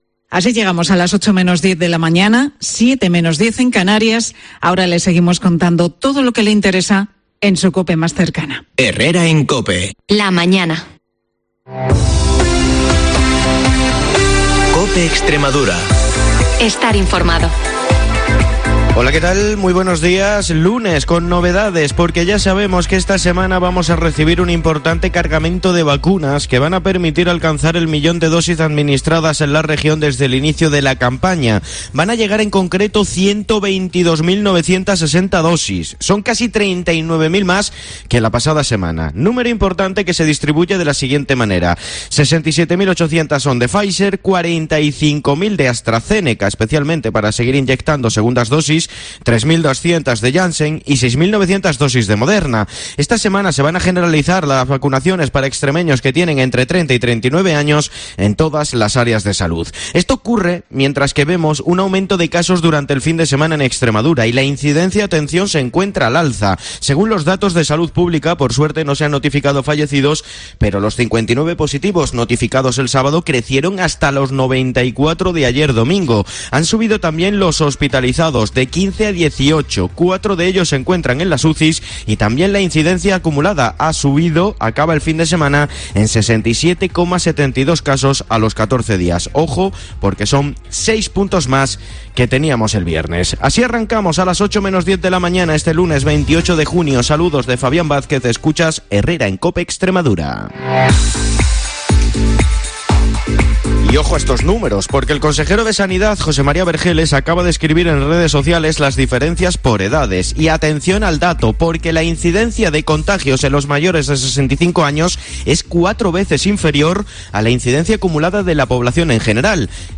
el informativo líder de la radio en la Comunidad Autónoma